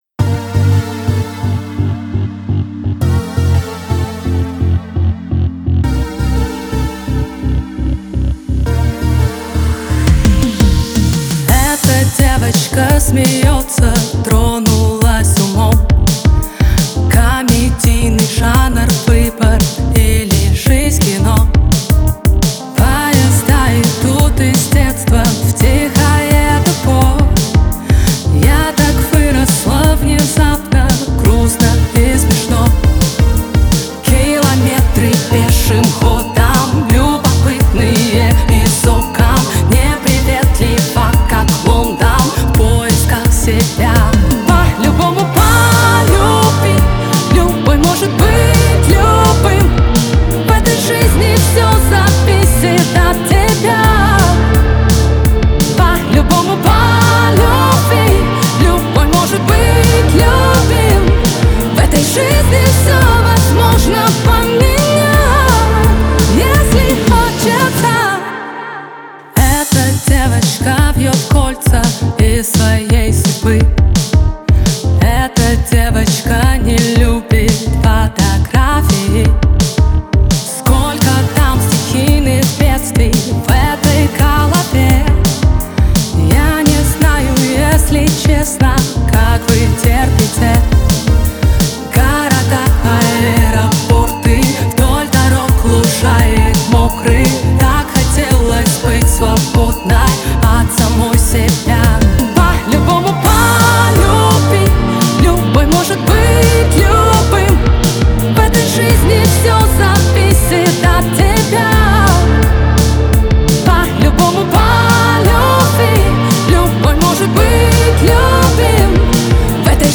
яркая и динамичная песня